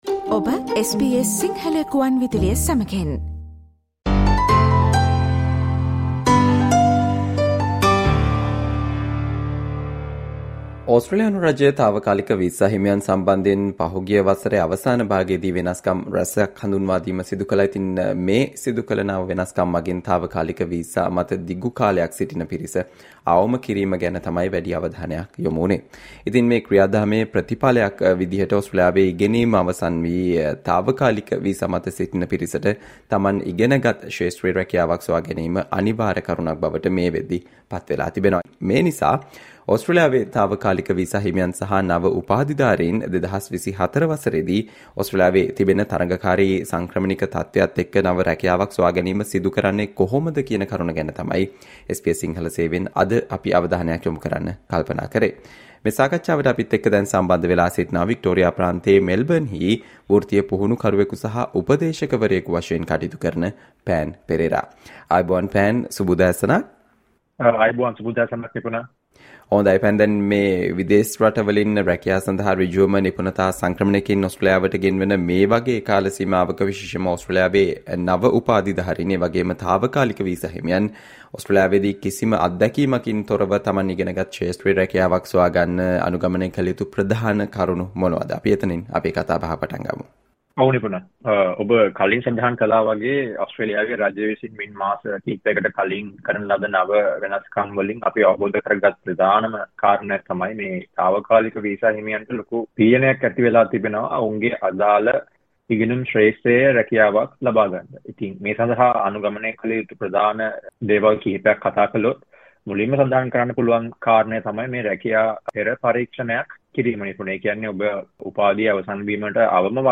ඕස්ට්‍රේලියාවේ පවතින තරඟකාරී සංක්‍රමණික තත්වය මත තාවකාලික වීසා හිමියන් සහ නව උපාධිධාරින් 2024 වසරේදී කිසිදු රැකියා පළපුරුද්දක් නොමැතිව නව රැකියාවක් සොයාගැනීම සිදු කරන්නේ කෙසේද යන්න සම්බන්ධයෙන් SBS සිංහල සේවය සිදු කල සාකච්චාවට සවන් දෙන්න